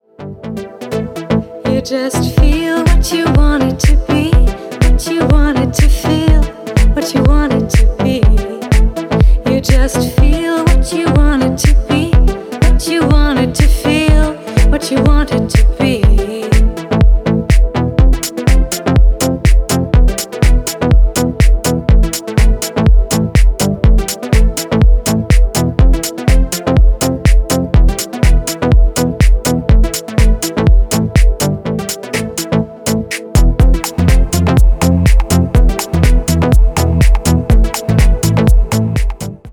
Громкие рингтоны
Танцевальные рингтоны